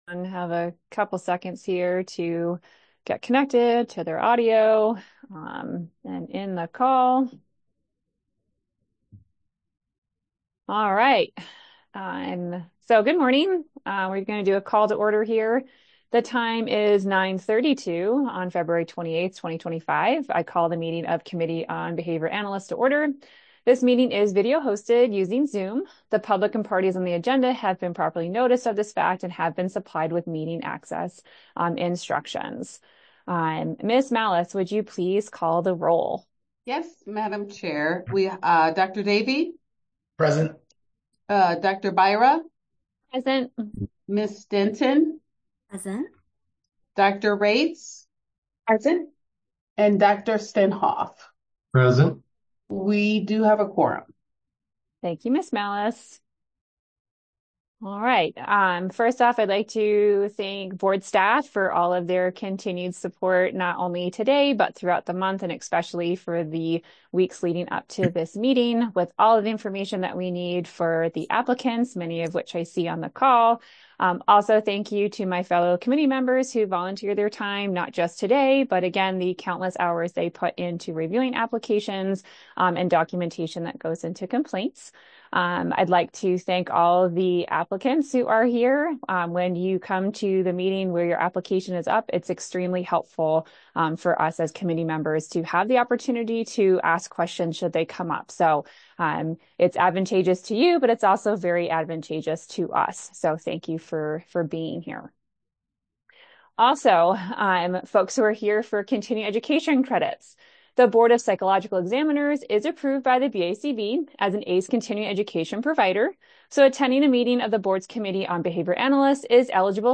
Members will participate via Zoom.